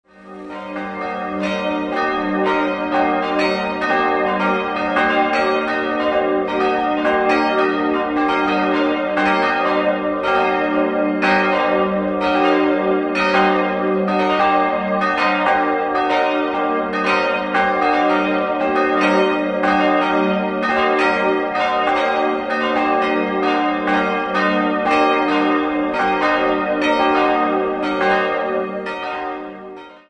Idealquartett: g'-b'-c''-es'' Die drei größeren Glocken wurden im Jahr 1950 von der Gießerei Czudnochowsky in Erding gegossen.